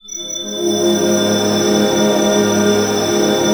SYNTH RIFF-R.wav